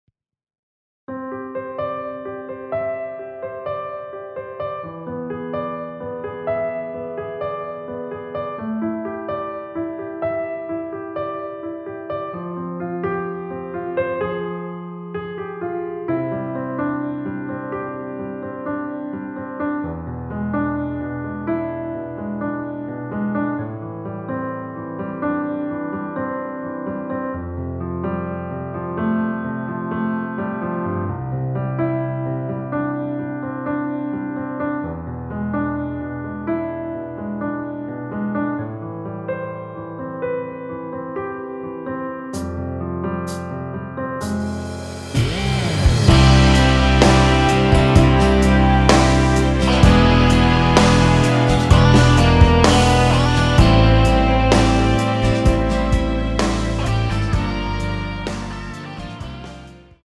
원키에서(-2)내린 (1절앞+후렴)으로 진행되게 편곡한 MR입니다.
앞부분30초, 뒷부분30초씩 편집해서 올려 드리고 있습니다.
중간에 음이 끈어지고 다시 나오는 이유는